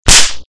spank.wav